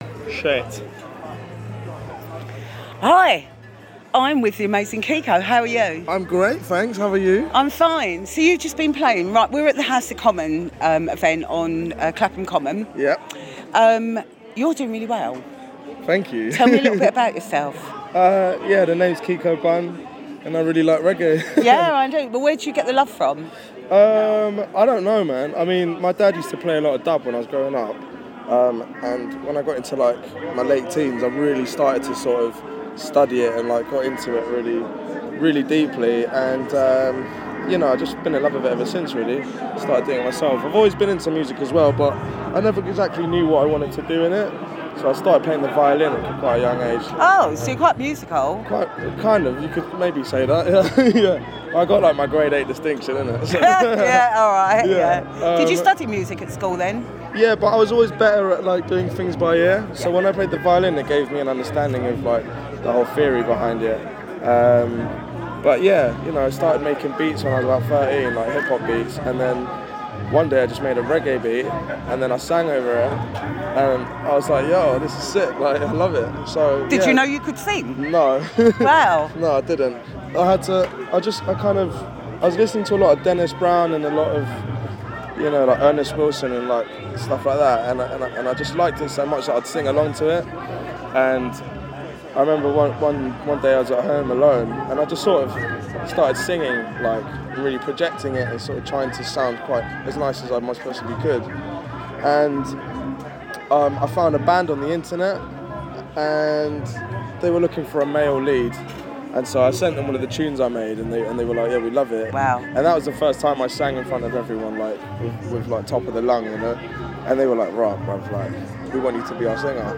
open the show at House Of Common festival on Clapham Common.